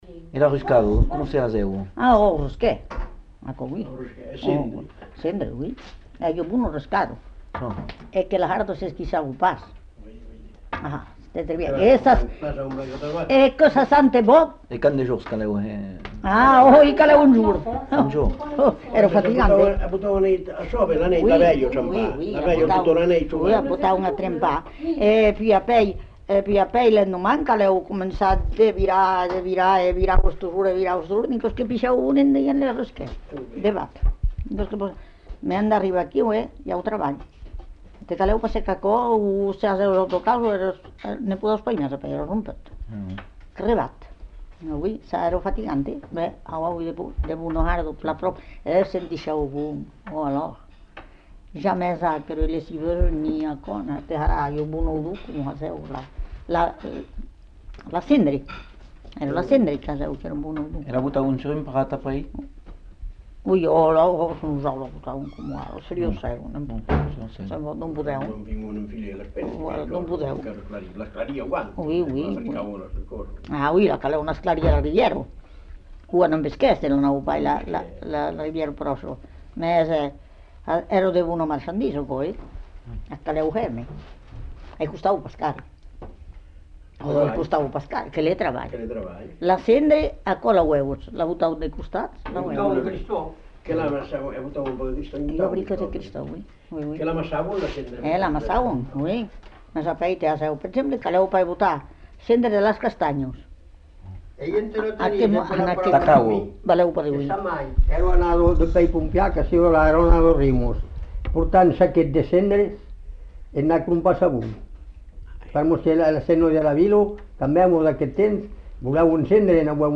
Lieu : Cazaux-Savès
Genre : témoignage thématique